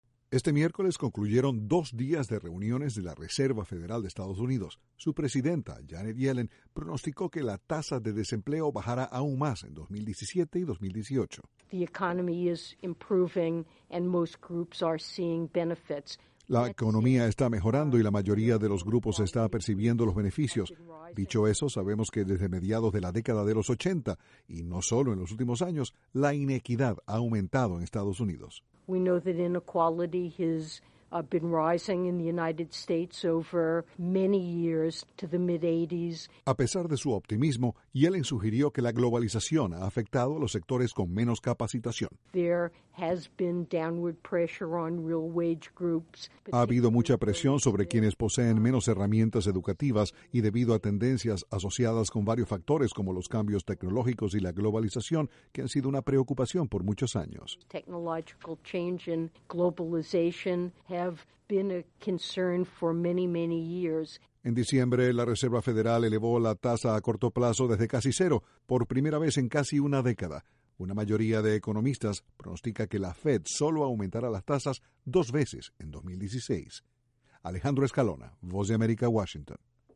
Según la presidenta del Banco Central de Estados Unidos, la economía del país está mejorando. Desde la Voz de América, Washington, informa